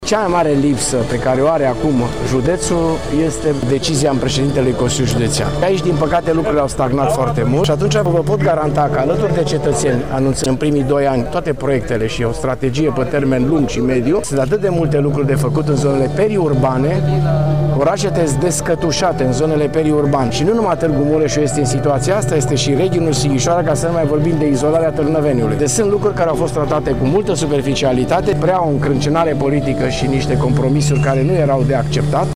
Dorin Florea, candidat la funcția de președinte al Consiliului Județean Mureș, spune că la nivel de județ sunt foarte multe lucruri de făcut în zonele periurbane: